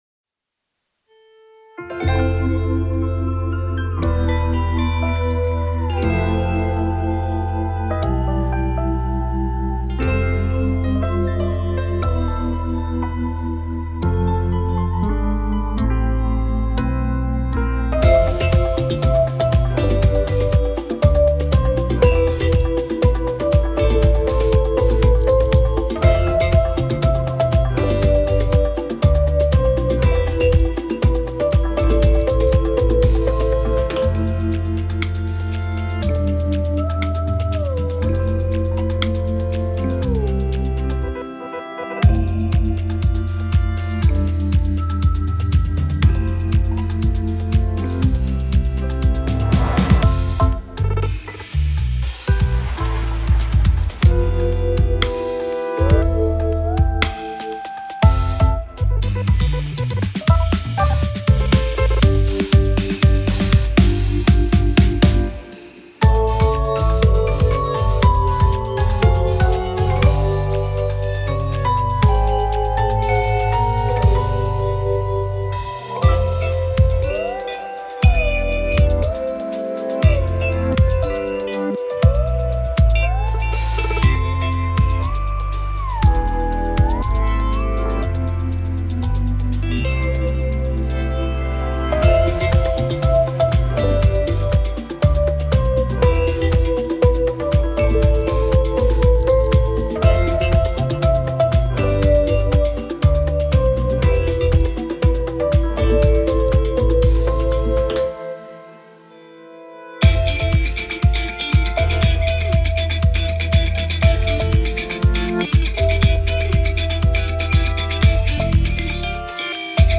歌なしで素材を利用したい方、歌ってみたなどでご利用の方はこちらをご利用ください。